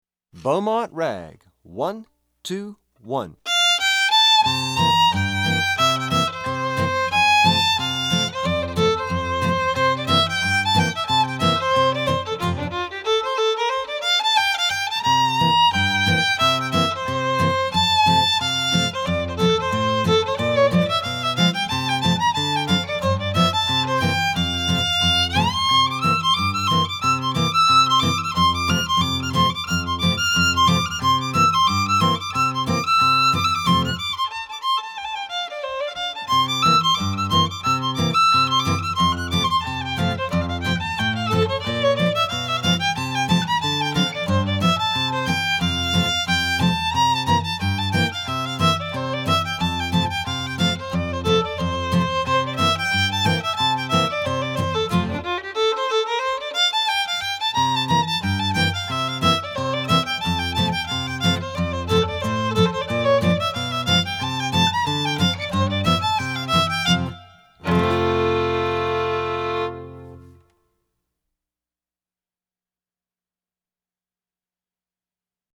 FIDDLE SOLO Fiddle Solo, Traditional, Hoedown
DIGITAL SHEET MUSIC - FIDDLE SOLO